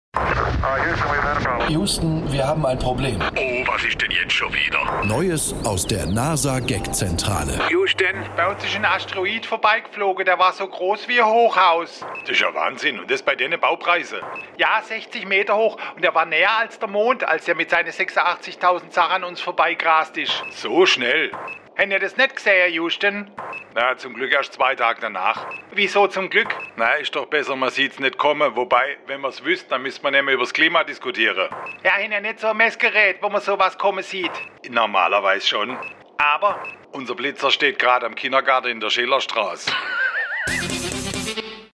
SWR3 Comedy Houston, mir hatten einen großen Asteroiden